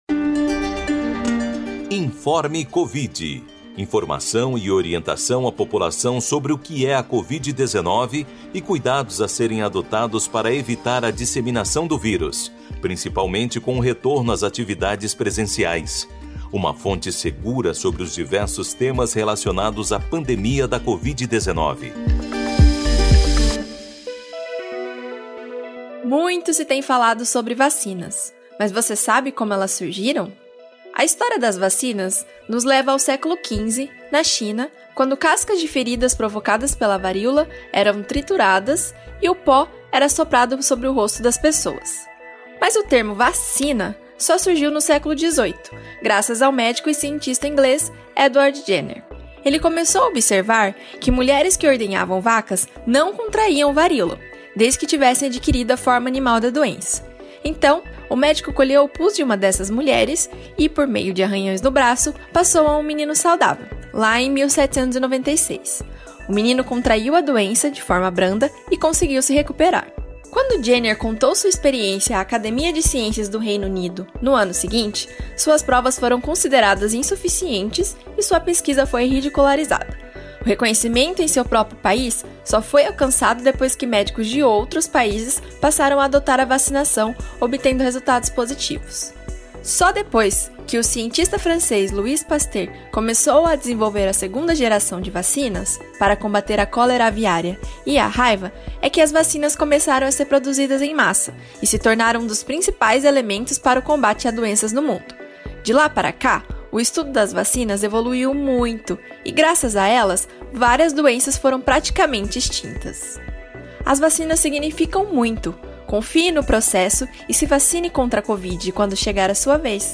Assim surgiu, na Rádio USP, o Informe Covid, um boletim diário com conhecimentos básicos sobre a pandemia de covid-19.